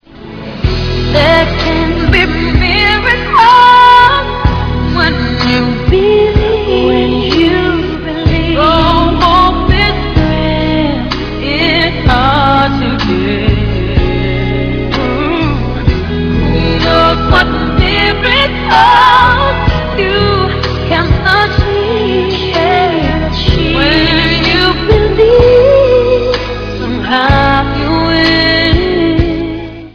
keyboards and drum programming